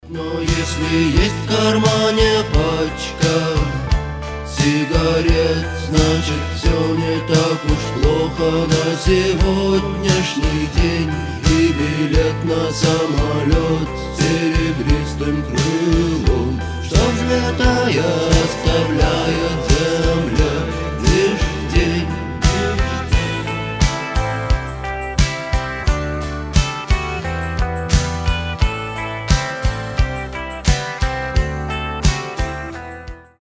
• Качество: 320, Stereo
80-е
психоделический рок